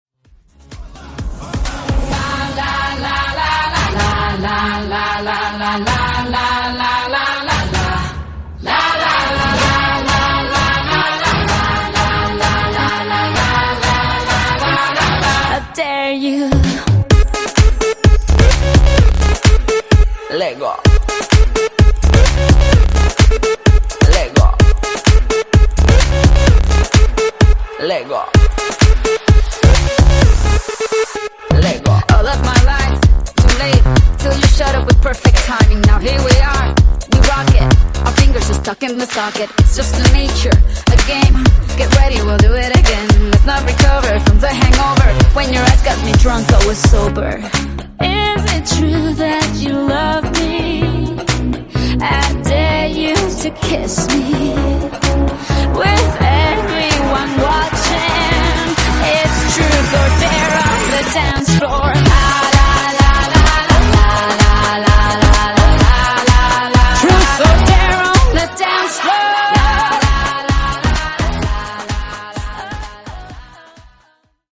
Genre: AFROBEAT
Clean BPM: 125 Time